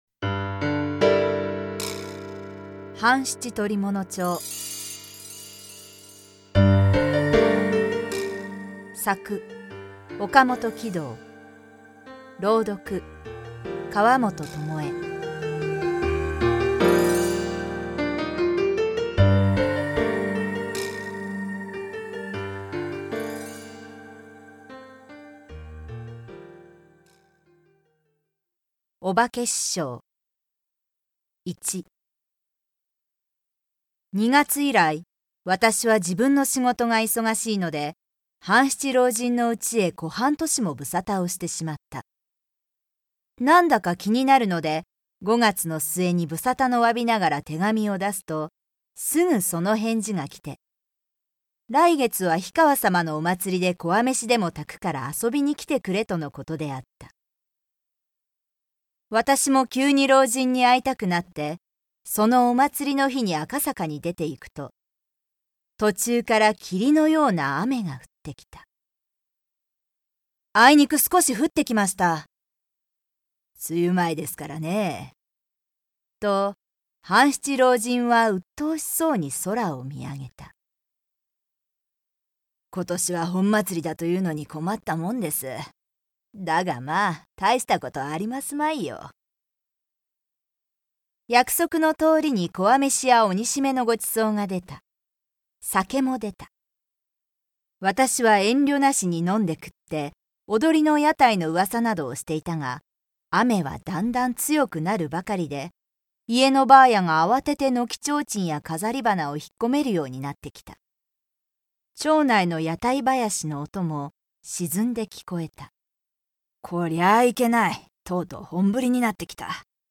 江戸のホームズ・半七親分の活躍を朗読で。